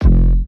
EDM Sub.wav